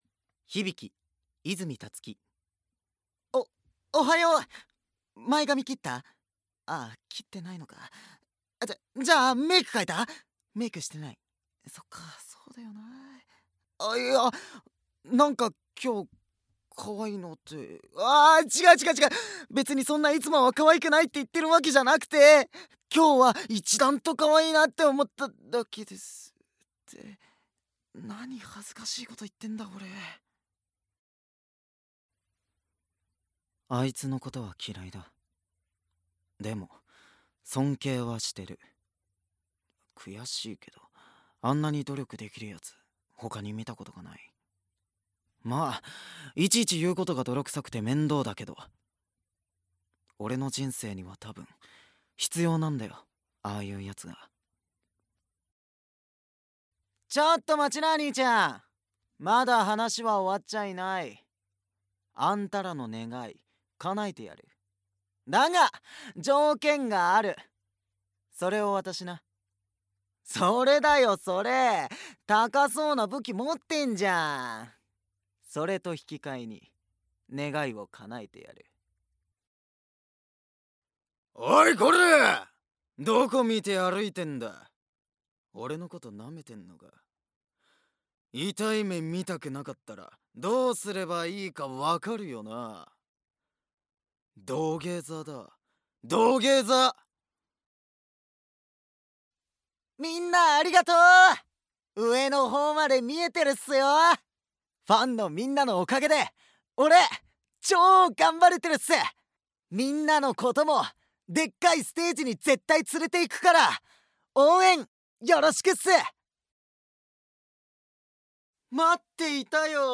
voice actor 男性所属声優